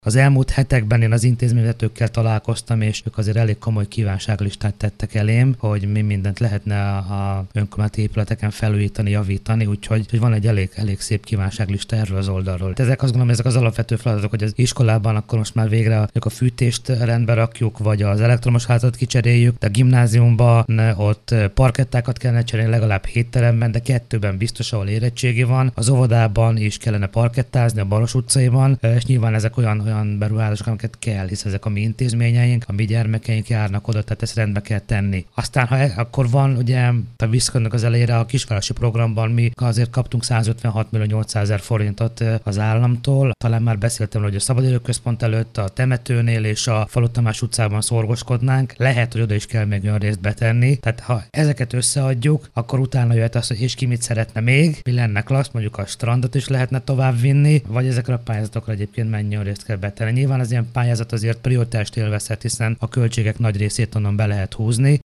Több nevelési-oktatási intézményben szeretnének felújításokat végezni Ócsán a Területfejlesztési Operatív Program keretében. Az óvoda, az iskola és a gimnázium is bejelentkezett a felújításokra. Bukodi Károly polgármestert hallják.